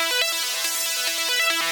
Index of /musicradar/shimmer-and-sparkle-samples/140bpm
SaS_Arp05_140-E.wav